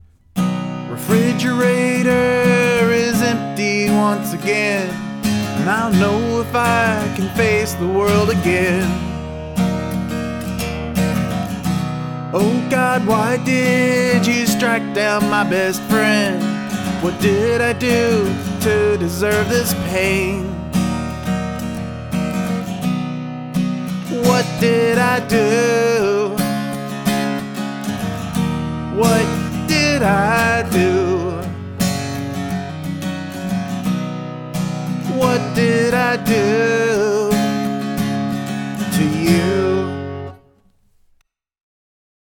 Acoustic and Vox
short n sweet
Vocals and acoustic sound nice.